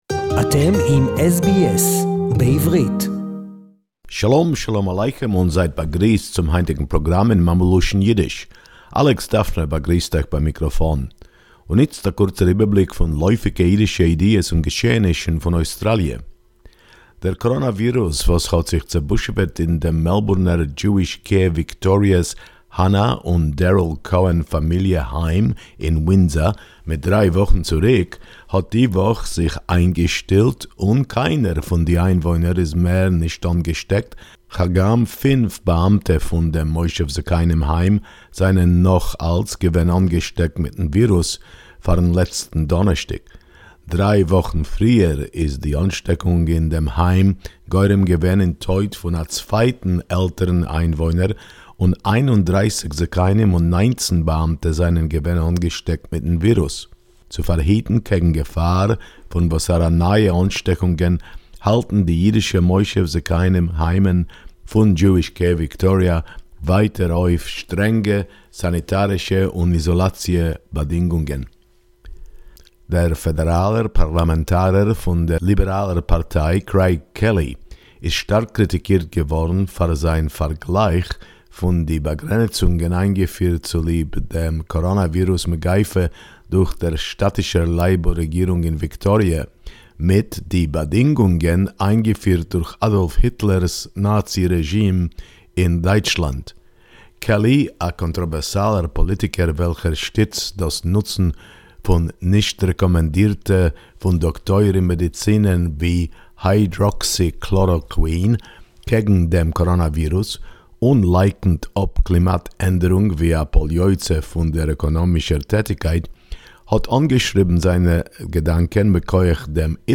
Yiddish report